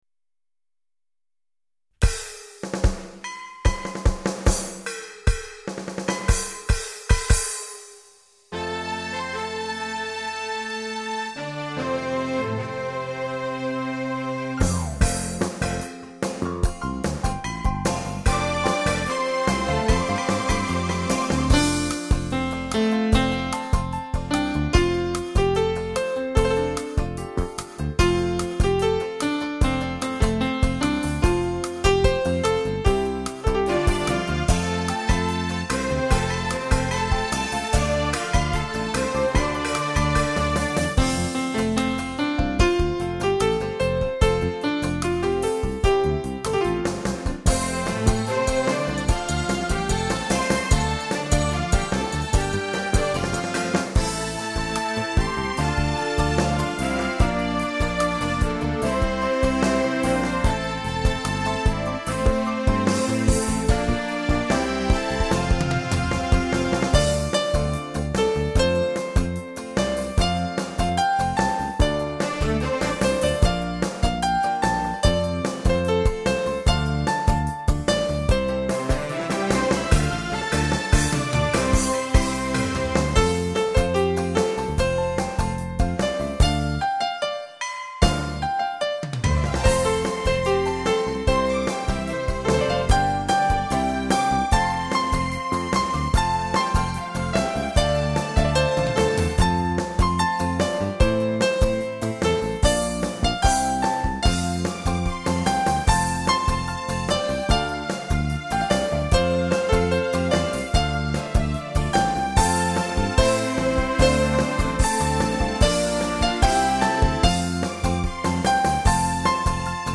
钢琴恋曲